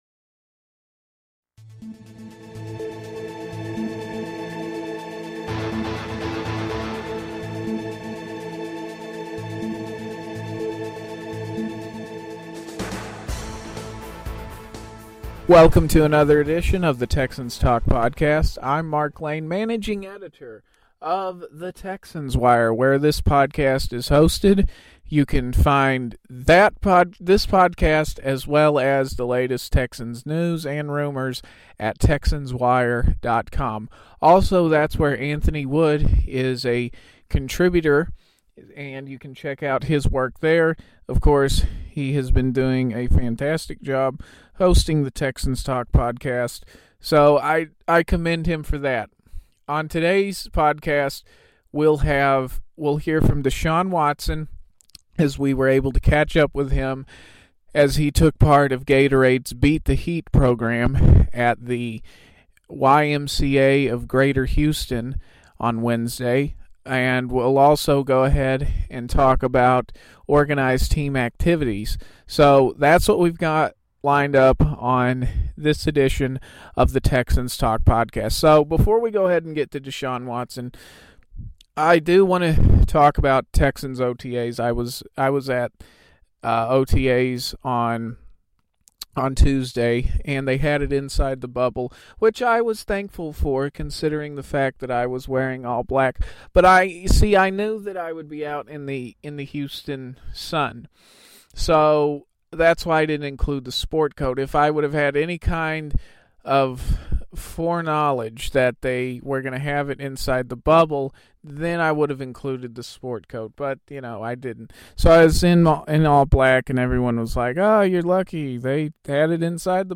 The Texans Talk Podcast had a chance to catch up with Houston QB Deshaun Watson as he took part in Gatorade's "Beat the Heat" program at the YMCA of Greater Houston.